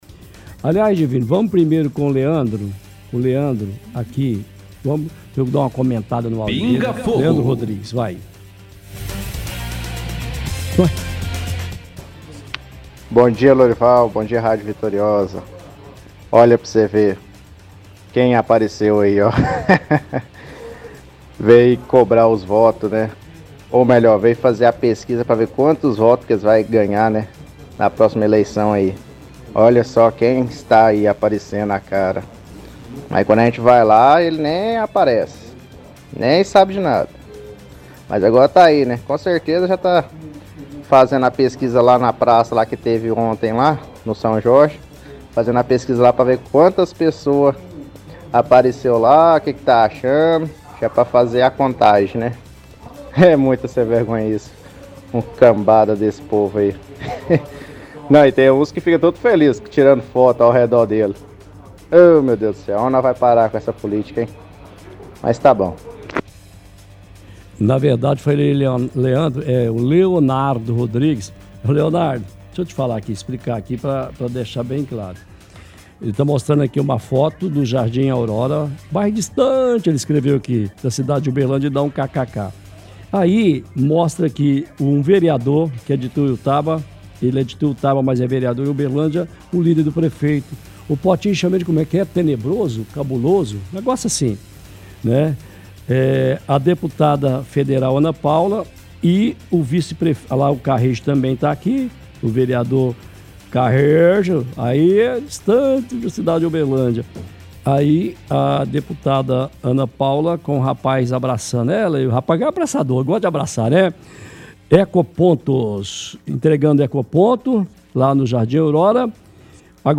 – Ouvinte relata políticos que estava fazendo campanha.